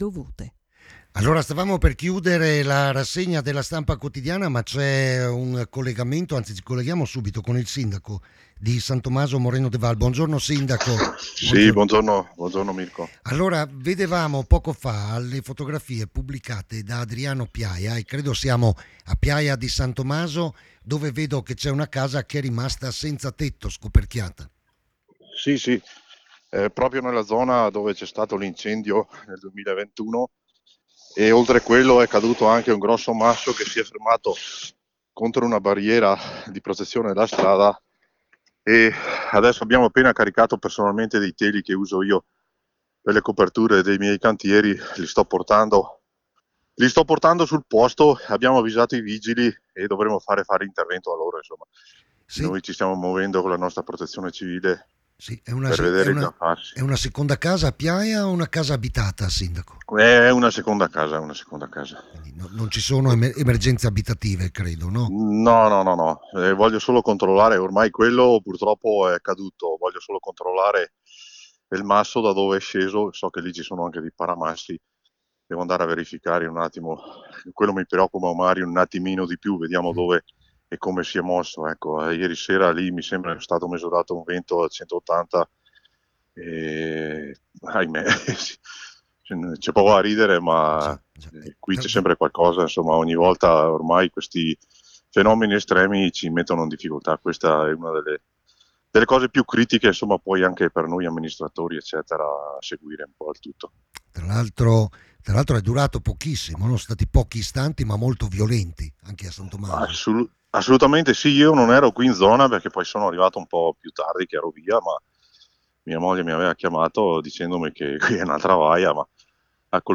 AUDIO OSPITE DELLA RASSEGNA STAMPA IL SINDACO DI SAN TOMASO, MORENO DE VAL